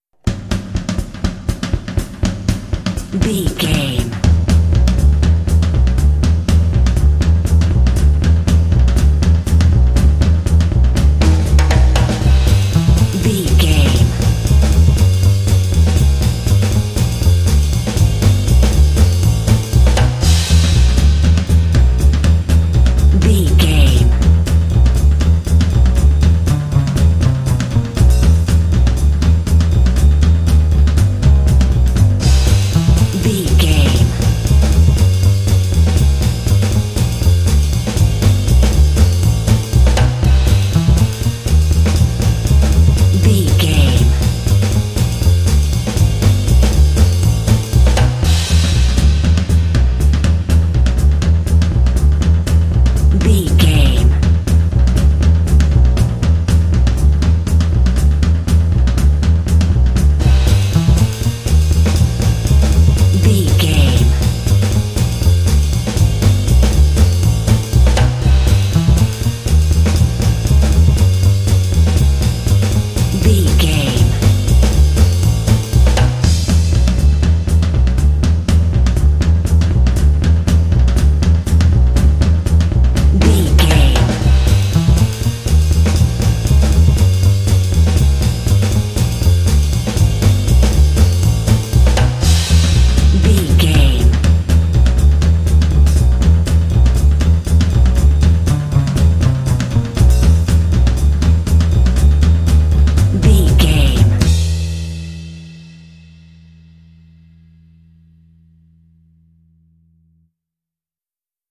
Uplifting
Aeolian/Minor
Fast
driving
energetic
lively
cheerful/happy
drums
double bass
big band
jazz